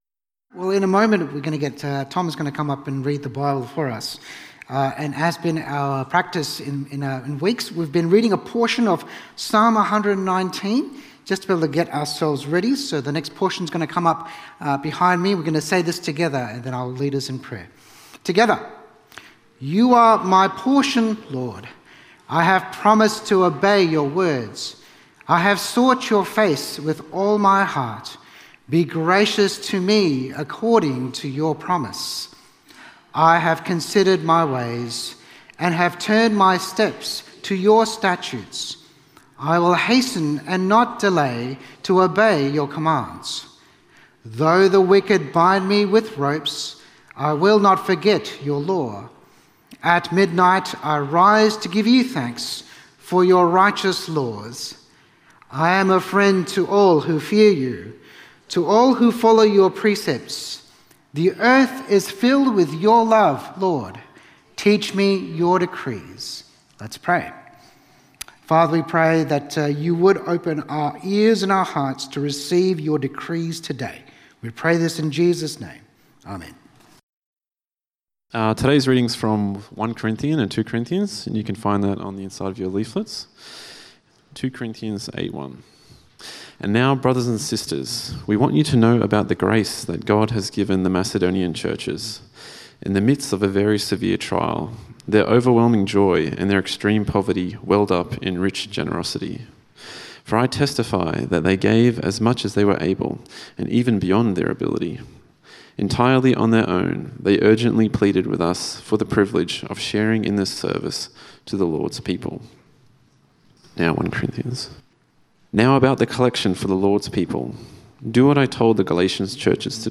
Gospel Generosity Sermon outline